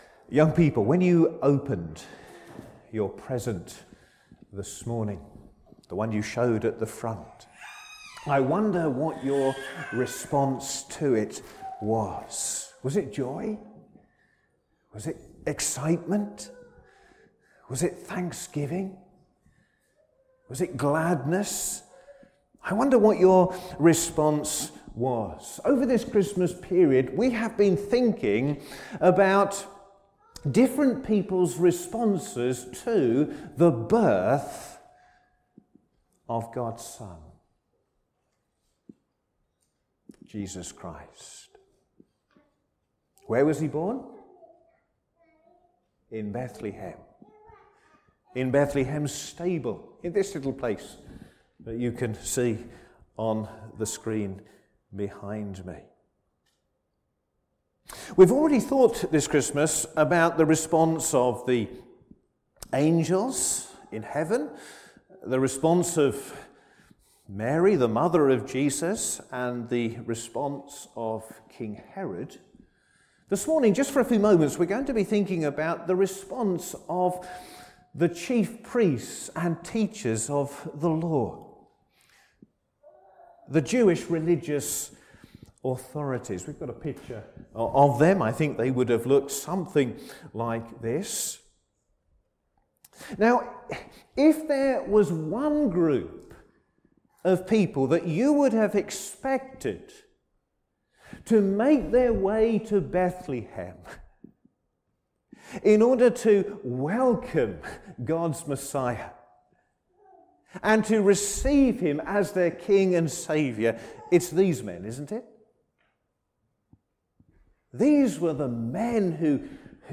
Sermon
Service Morning